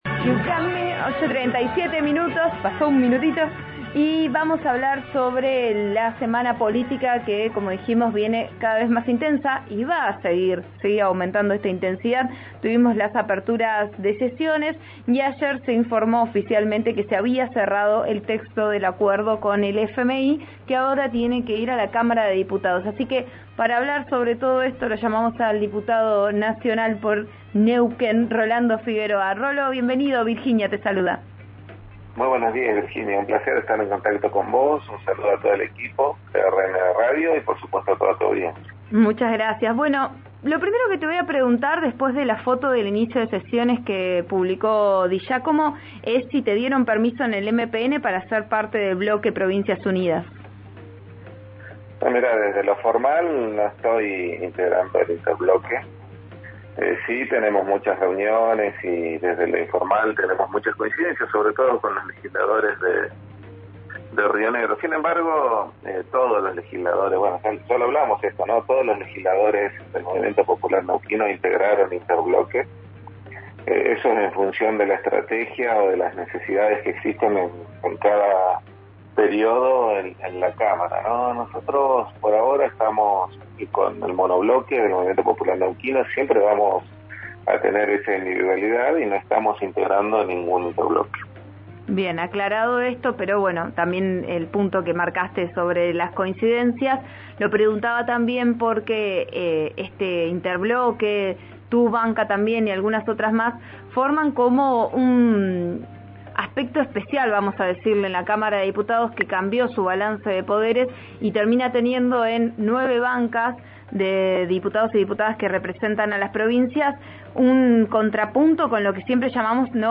Esta mañana, en diálogo con «Vos A Diario» (RN Radio), el neuquino confirmó que no es parte del interbloque.